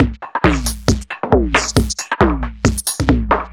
Index of /musicradar/uk-garage-samples/136bpm Lines n Loops/Beats
GA_BeatRingB136-04.wav